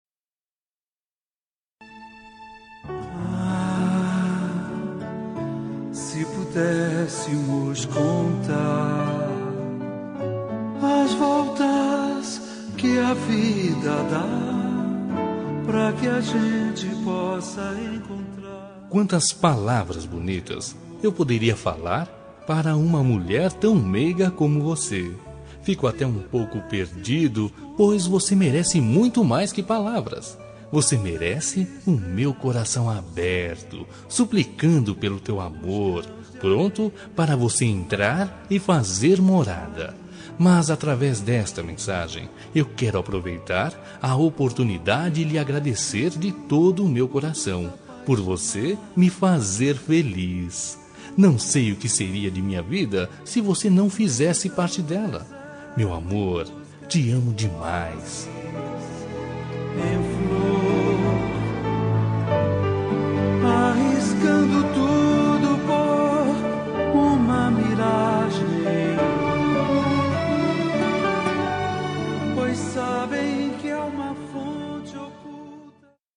Telemensagem de Agradecimento – Romântica – Voz Masculina – Cód: 28